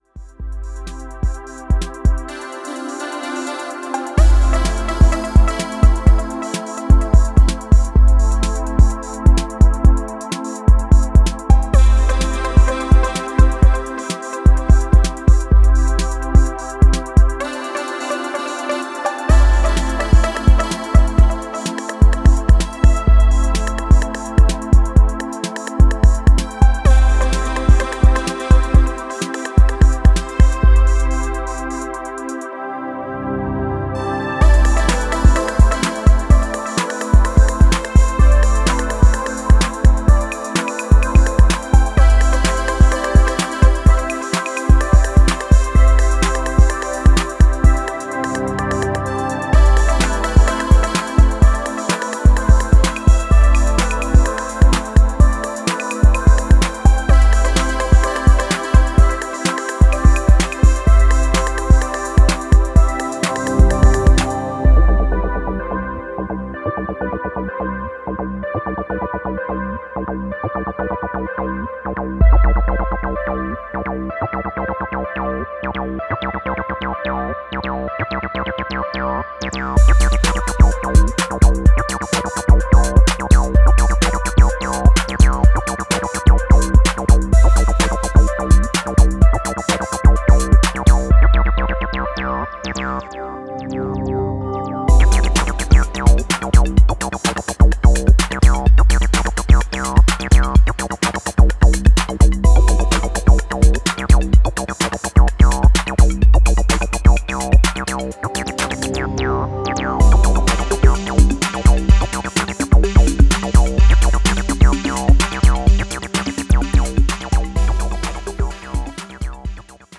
an acid tinged remix
House